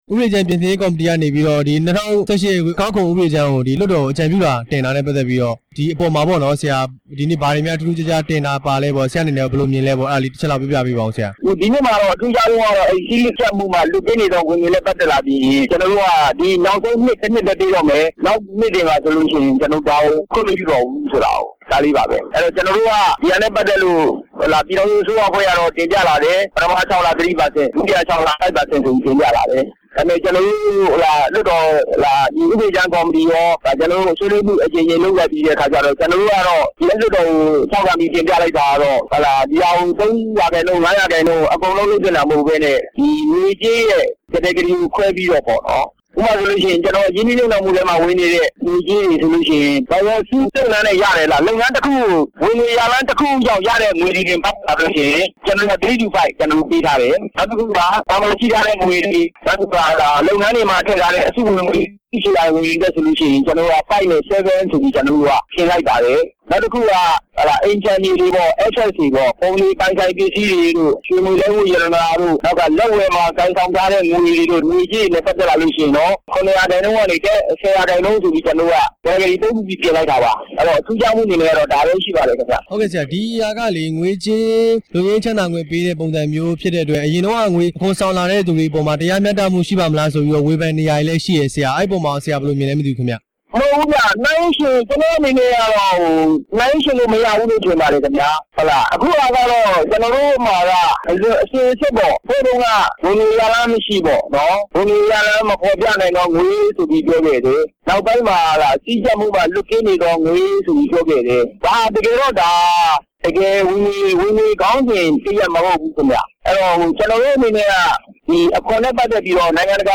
၂၀၁၈ ခုနှစ် အခွန်အကောက် ဥပဒေကြမ်း အစီရင်ခံစာ မေးမြန်းချက်